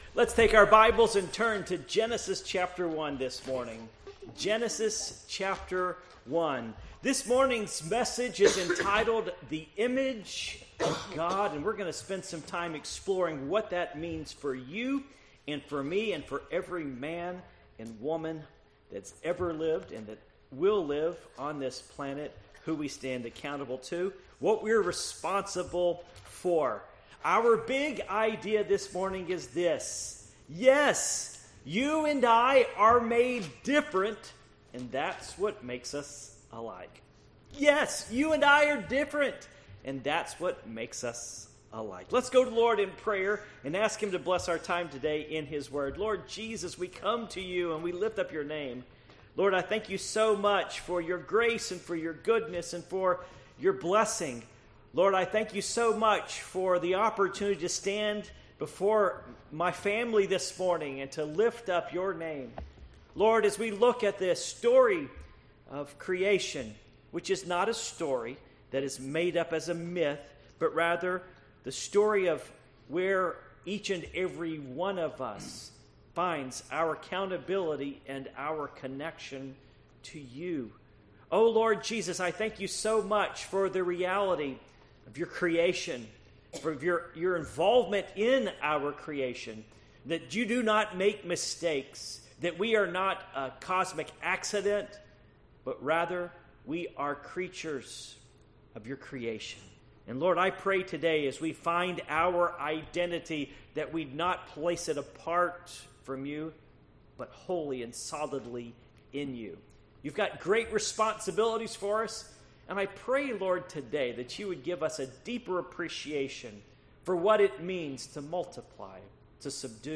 Passage: Genesis 1:26-31 Service Type: Morning Worship